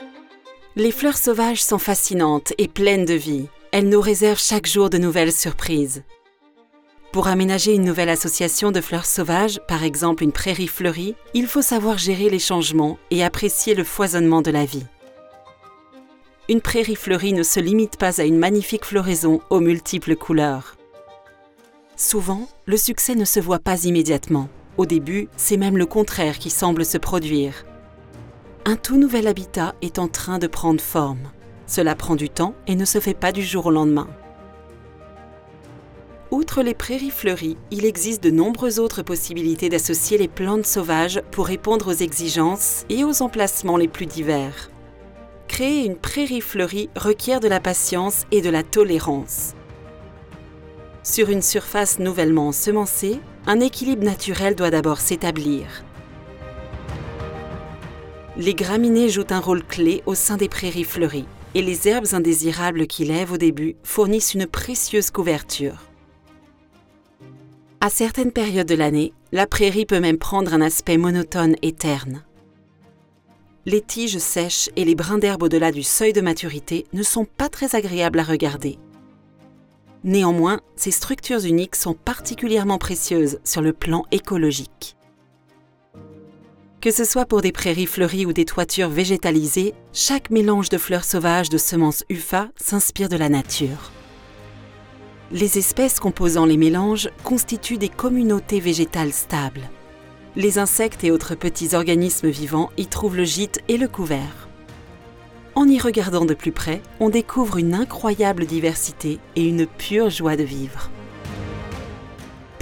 Voice Artists - Narration